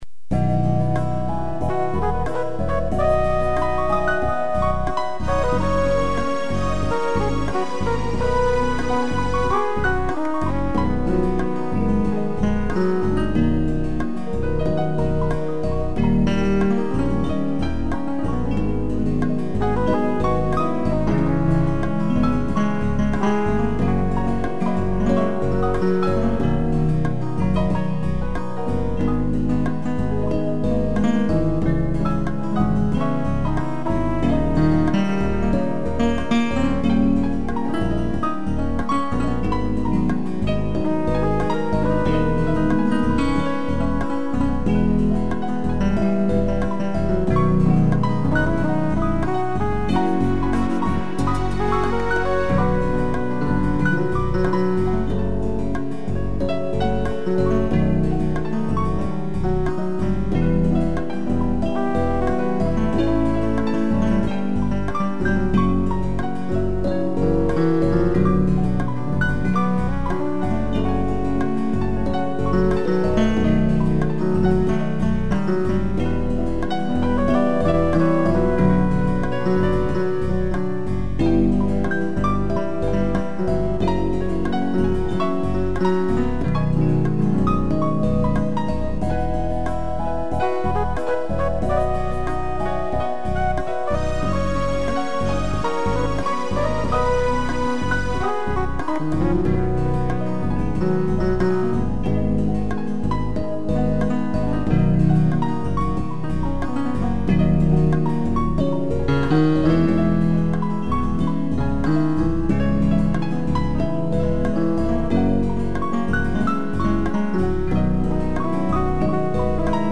piano e flugelhorn
(instrumental)